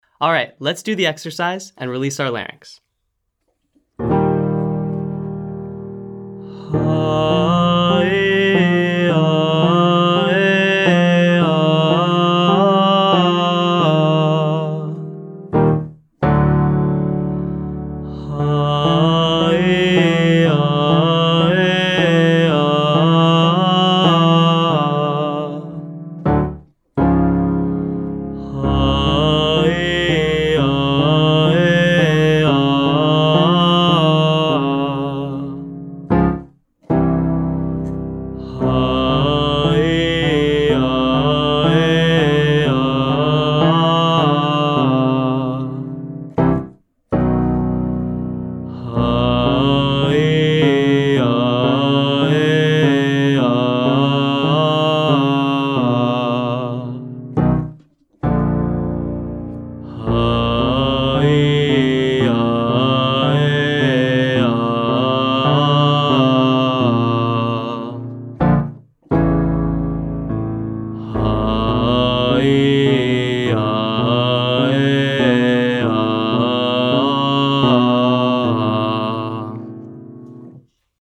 So in general, use a relaxed, medium-volume chest voice sound and allow your larynx to float and stay flexible and open.
I’ll sing as low as I can as a Tenor and then continue to play the piano a little lower for any Basses out there.